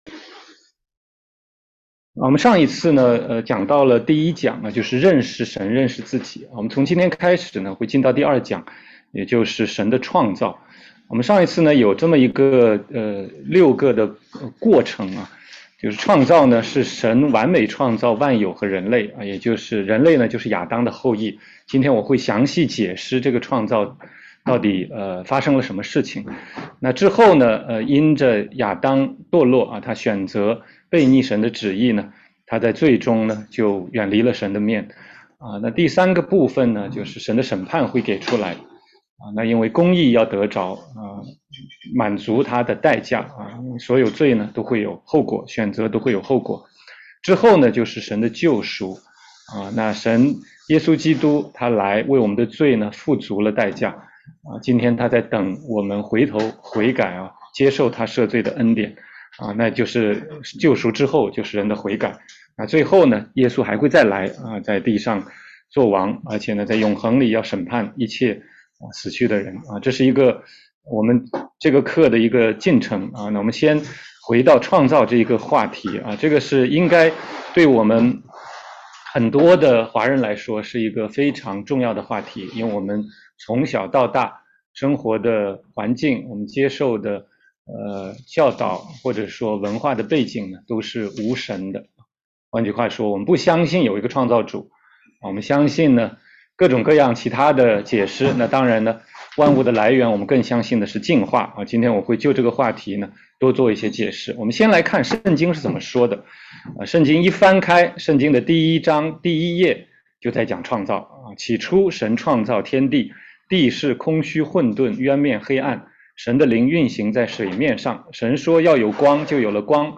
16街讲道录音 - 得救的福音第二讲：神创造